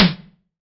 POP SNARE.wav